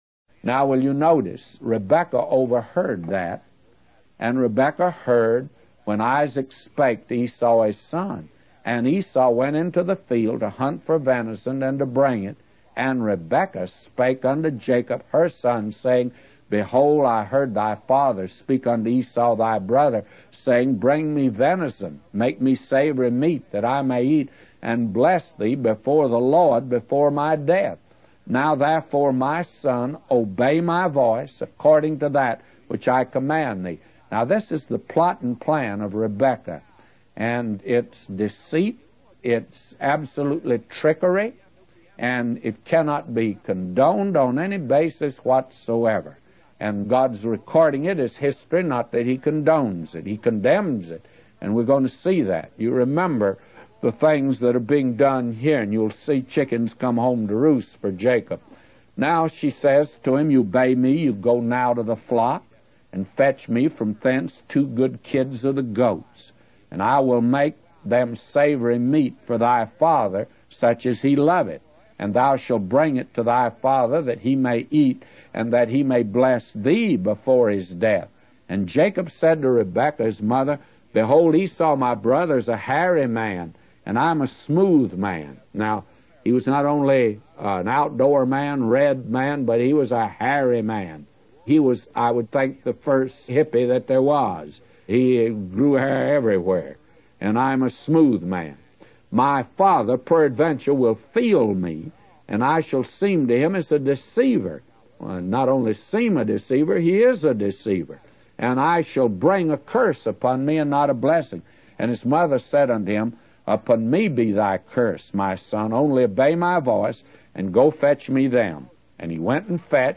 In this sermon, the preacher discusses the deceitful actions of Rebekah towards her husband Isaac.
Sermon Outline